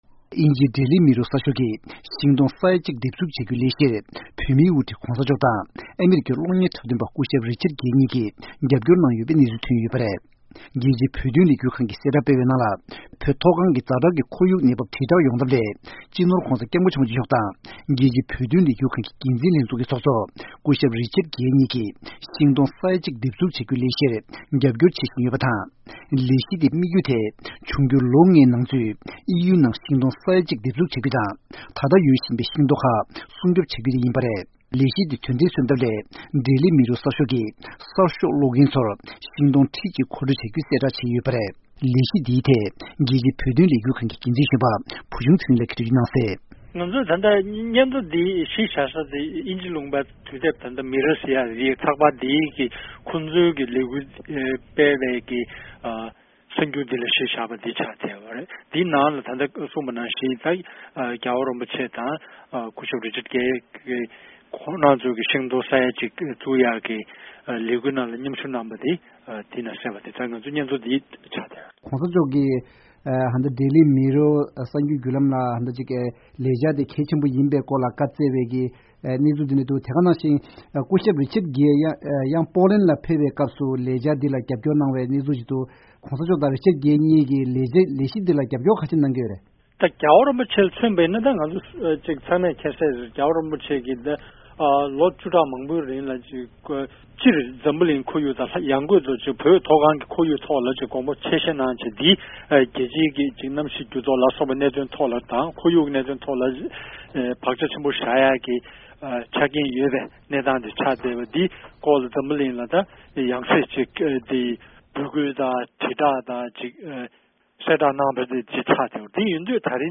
གནས་ཚུལ་སྙན་སྒྲོན་ཞུ་ཡི་རེད།